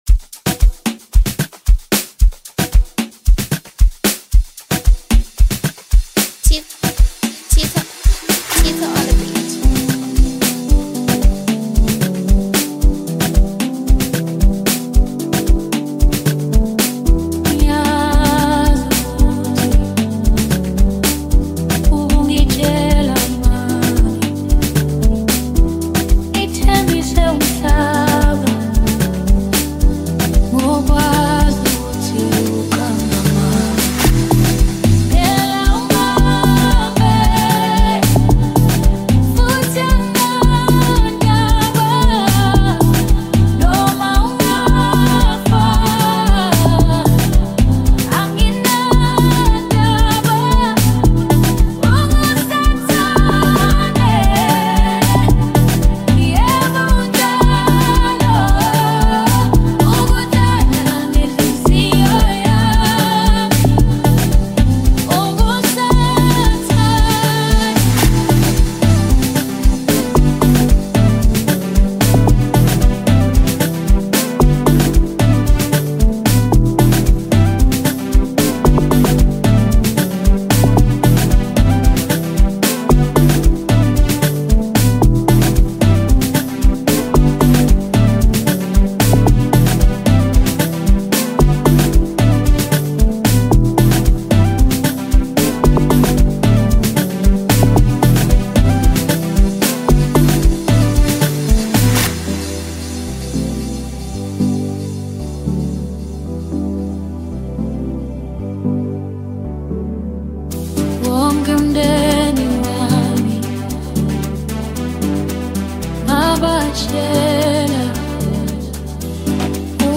Home » Amapiano » Maskandi